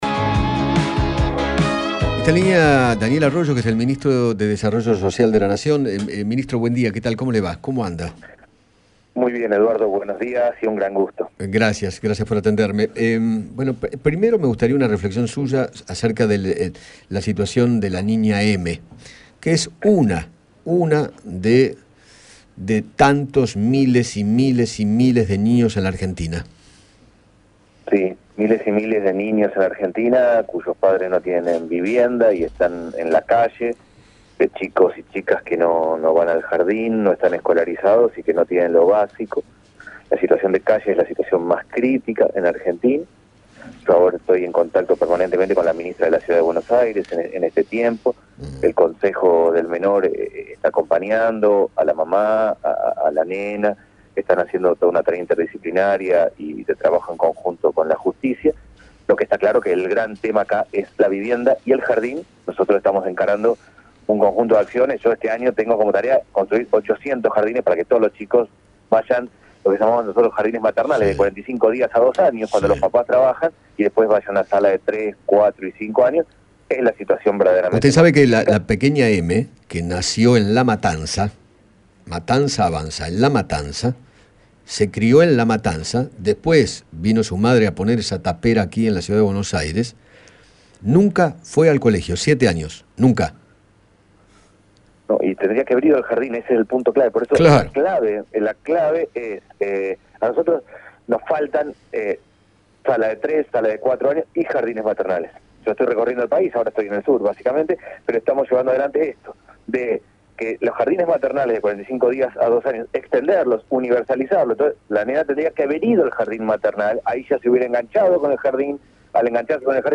Daniel Arroyo, ministro de Desarrollo Social, dialogó con Eduardo Feinmann sobre la crítica situación que se está viviendo con la gente en la calle, la escasez de viviendas y la falta de vacantes en los jardines.